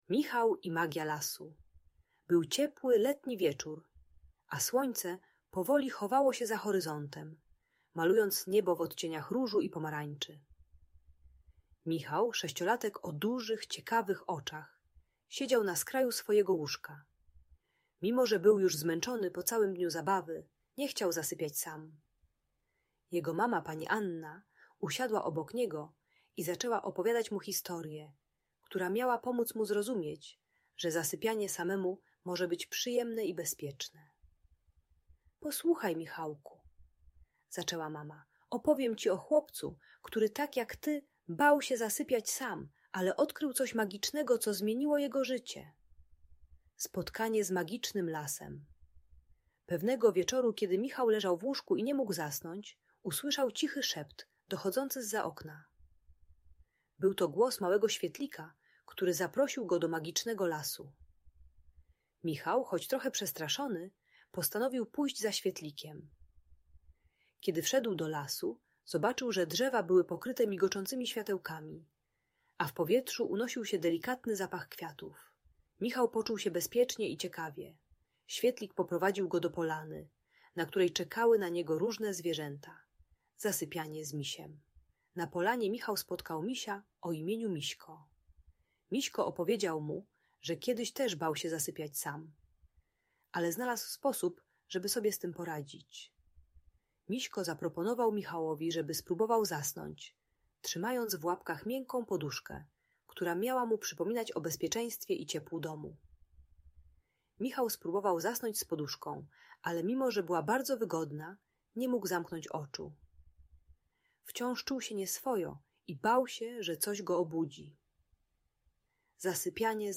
Historia Michała i Magia Lasu - Audiobajka